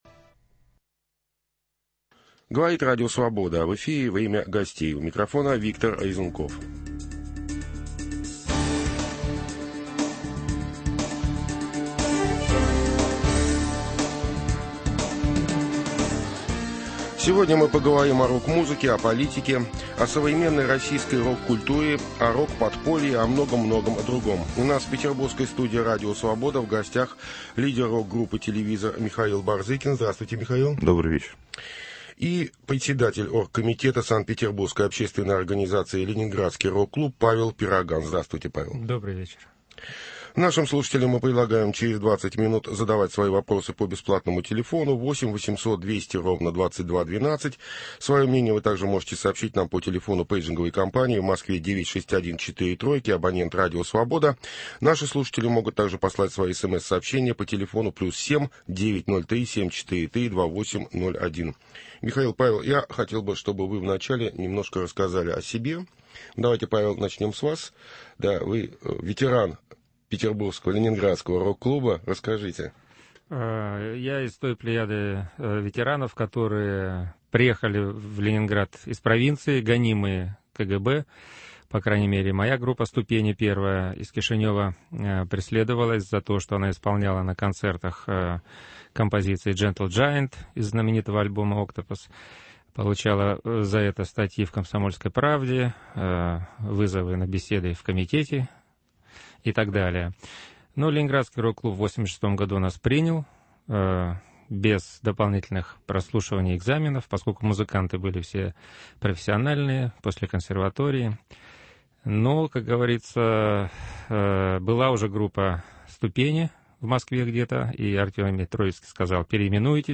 О политике, о "Маршах несогласных", о новом "застое", о "попсе", о возрождении "Ленинградского Рок-клуба" беседуют петербургские рок-музыканты